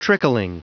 Prononciation du mot trickling en anglais (fichier audio)
Prononciation du mot : trickling